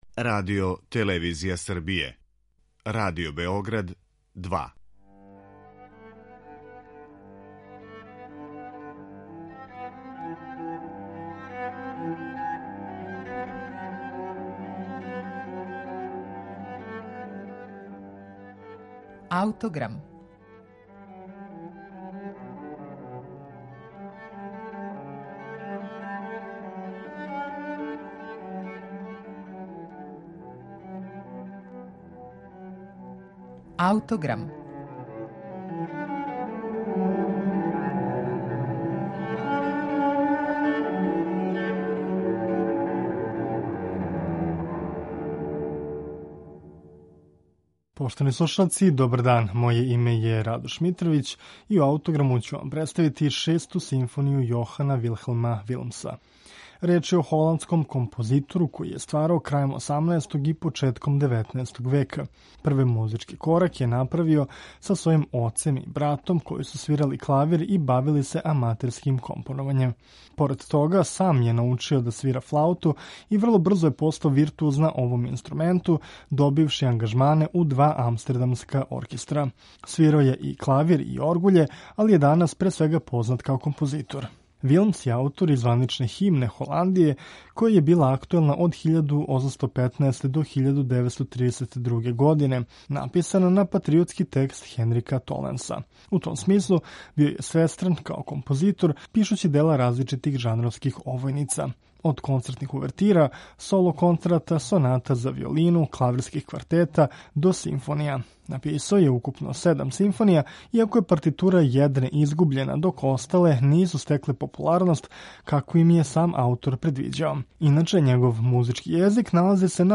Ми ћемо слушати Шесту симфонију из 1820. године, која почива на класицистичкој поетици, са елементима раног романтизма.